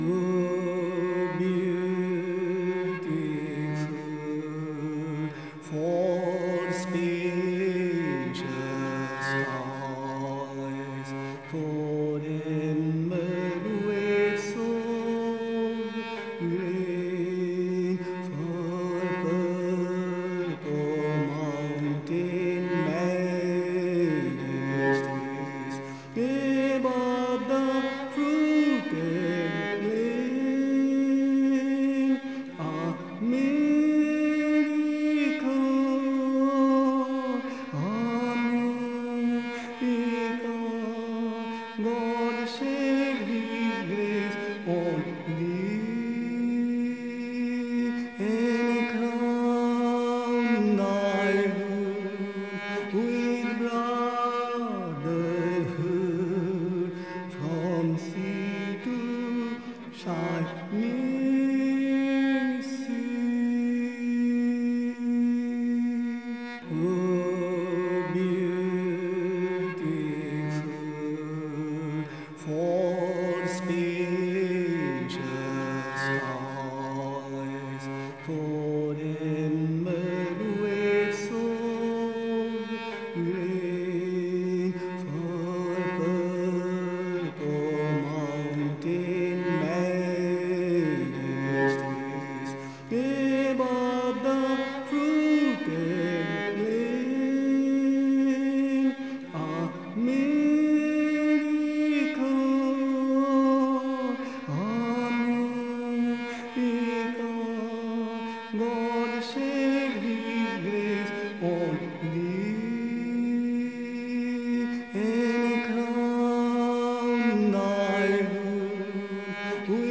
Sri Chinmoy soulfully sings the well-known song “America The Beautiful”.